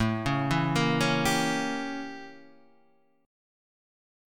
A 7th Flat 9th